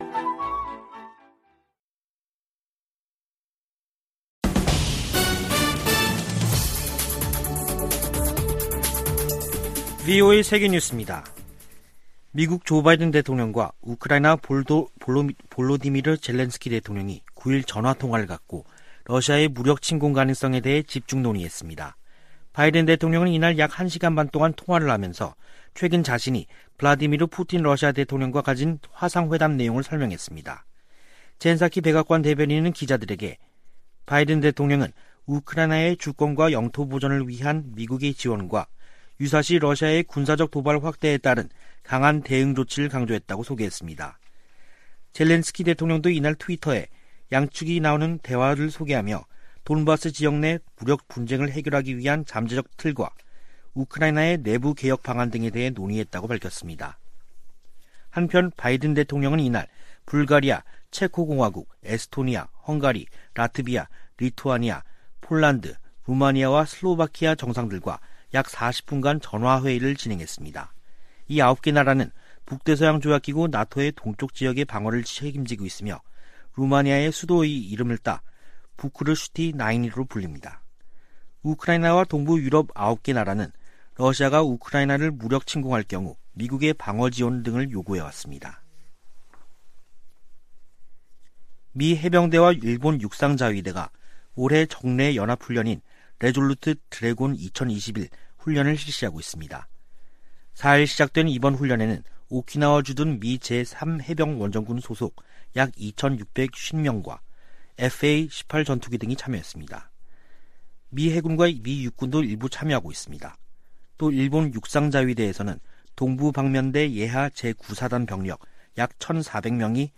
VOA 한국어 간판 뉴스 프로그램 '뉴스 투데이', 2021년 12월 10일 2부 방송입니다. 조 바이든 대통령은 세계 민주주의와 인권이 도전에 직면했다며 지속적인 노력이 필요하다고 강조했습니다. 올해도 유엔 안전보장이사회에서 북한 인권에 대한 공개 회의가 열리지 않는다고 미 국무부가 밝혔습니다. 북한에 각종 디지털 기기들이 보급되면서 외부 정보와 문화에 귀기울이는 젊은이들이 늘고 있습니다.